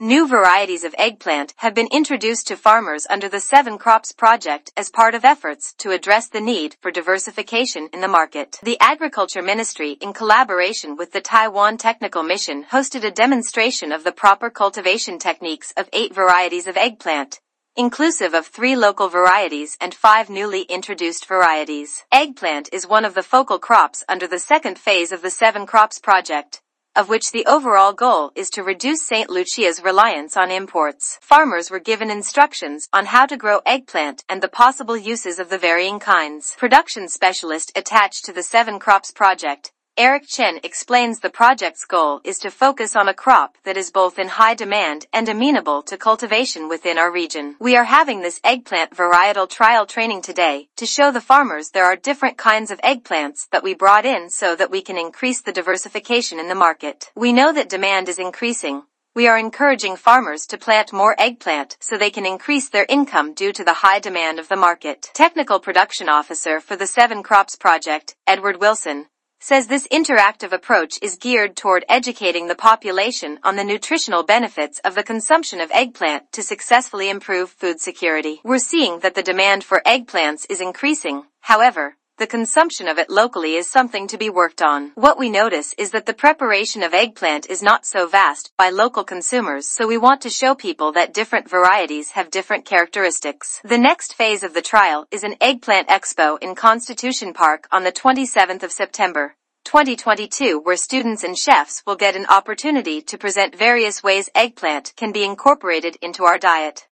Play Press Release